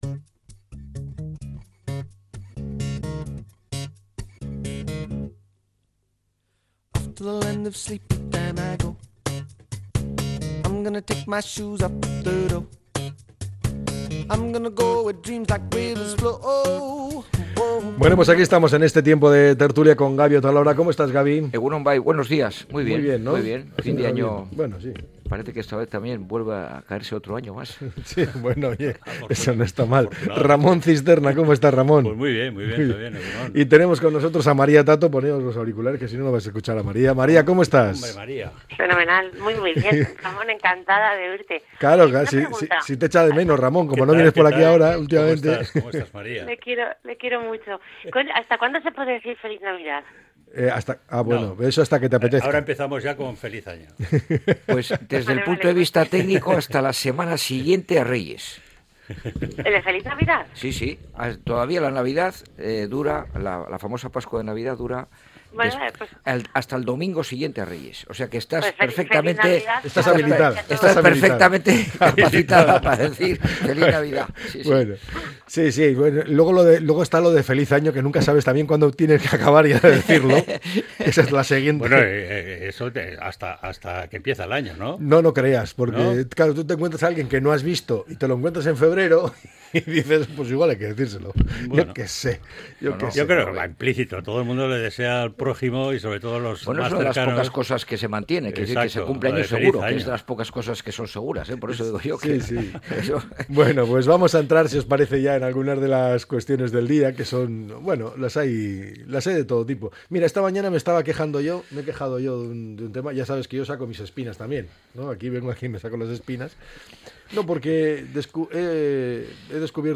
La tertulia 29-12.